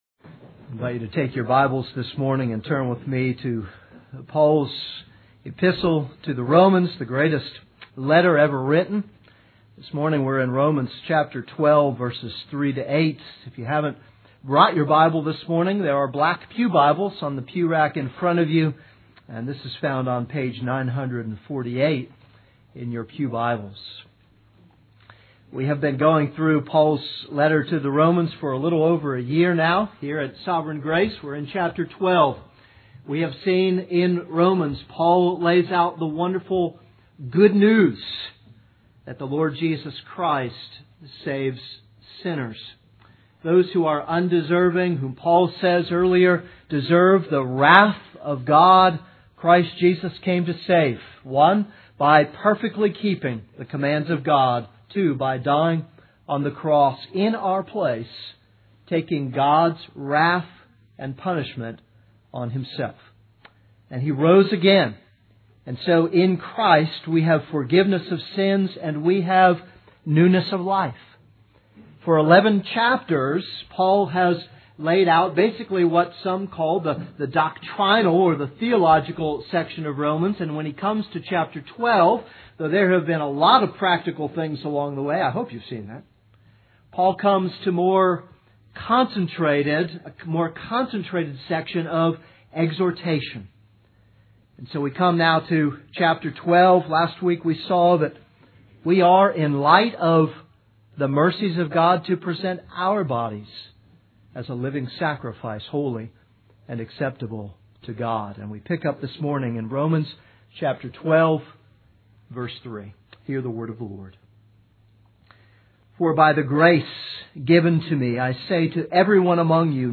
This is a sermon on Romans 12:3-8.